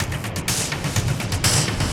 RI_DelayStack_125-03.wav